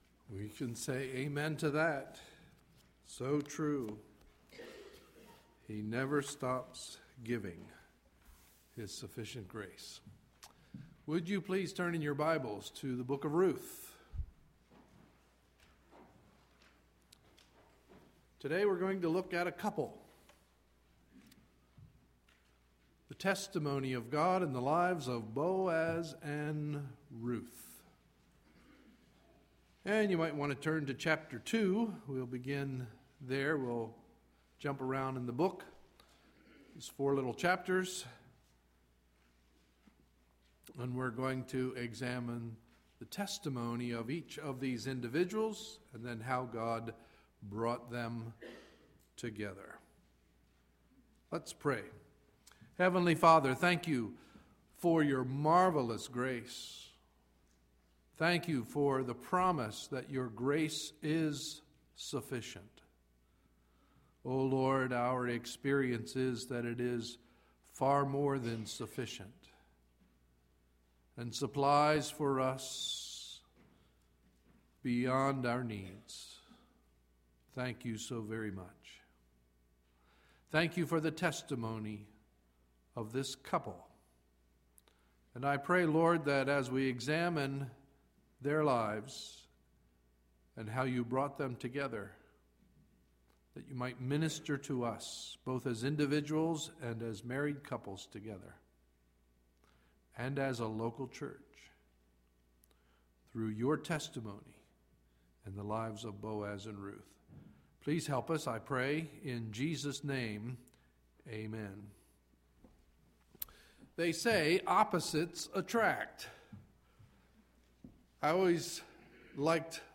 Sunday, February 12, 2012 – Morning Message